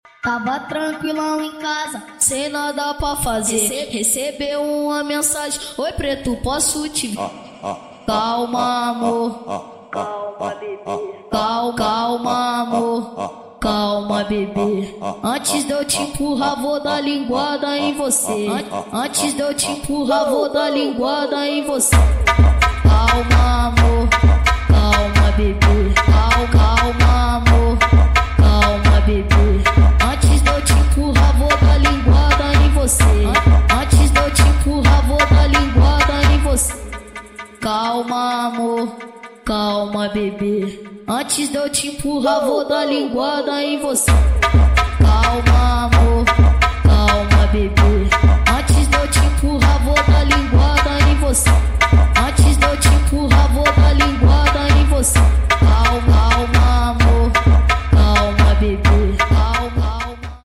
8D 🎧🎶